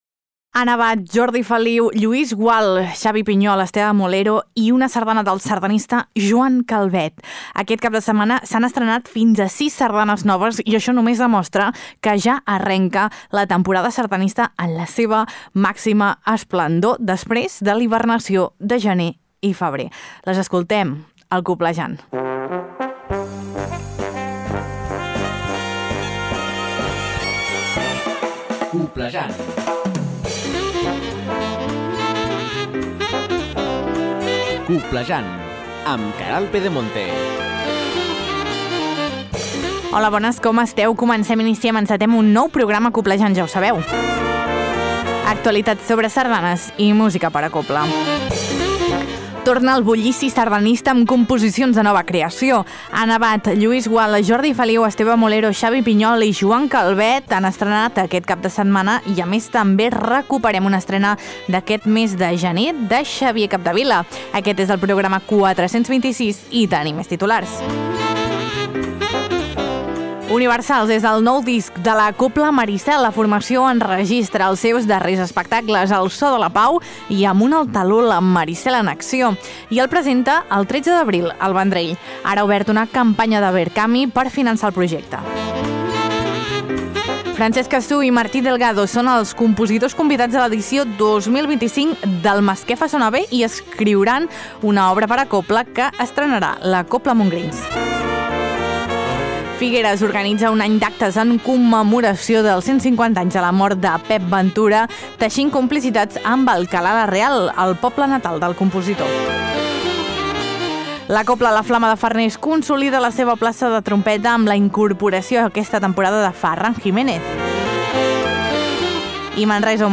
Tot això i molt més a Coblejant, un magazín de Ràdio Calella Televisió amb l’Agrupació Sardanista de Calella i en coproducció amb La Xarxa de Comunicació Local que s’emet per 75 emissores a tots els Països Catalans. T’informa de tot allò que és notícia al món immens de la sardana i la cobla.